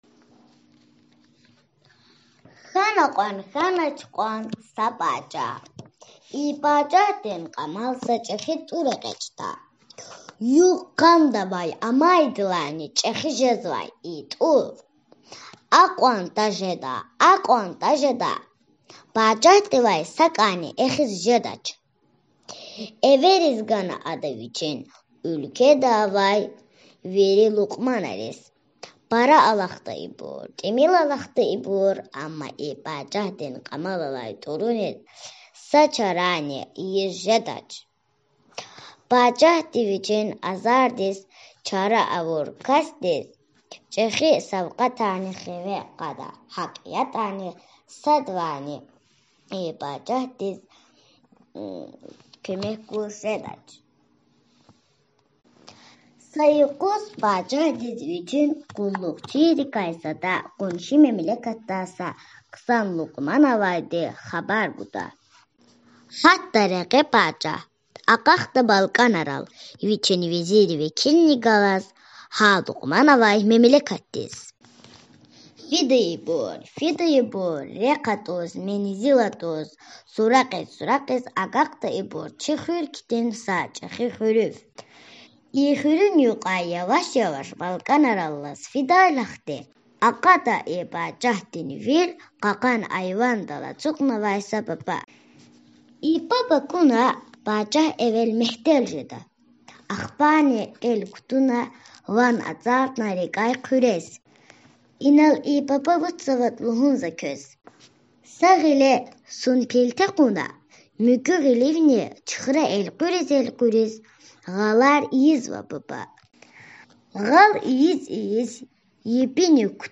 Кьамал тIур алай пачагь (аудио мах)